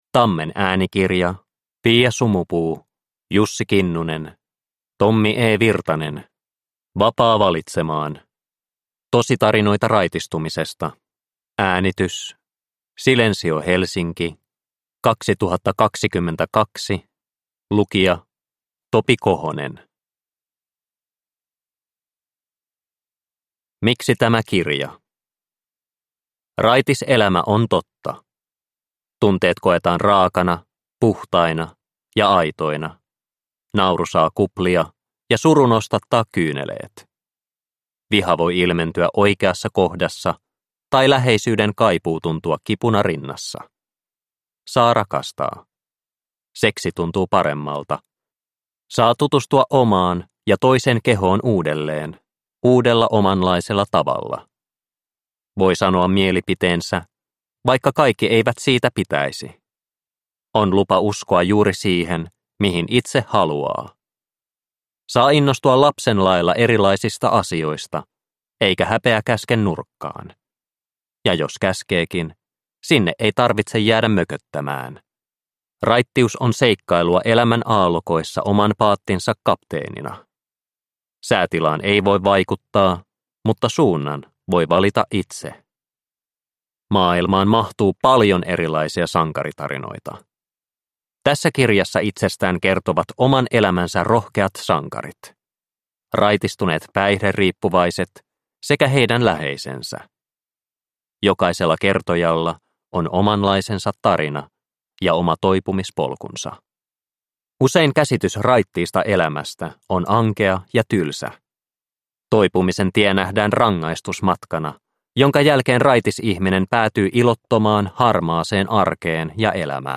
Vapaa valitsemaan – Ljudbok – Laddas ner